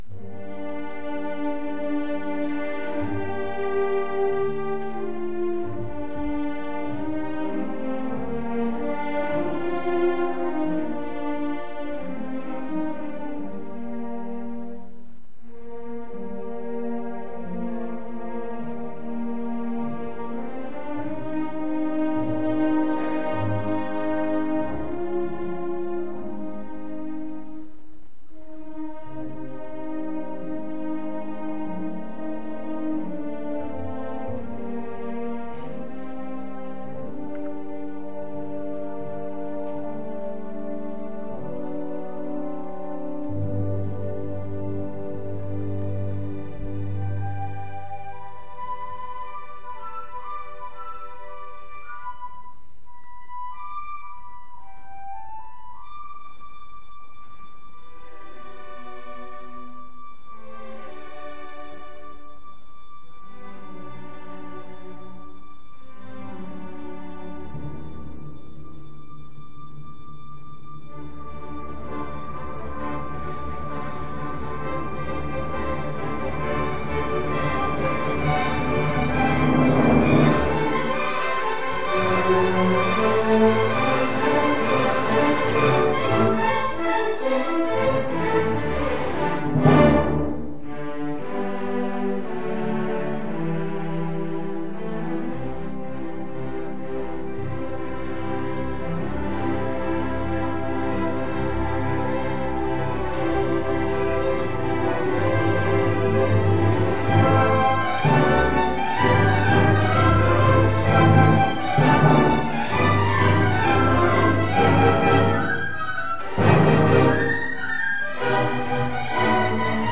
Orchestra
Style: Classical
symphony-8-op-88.mp3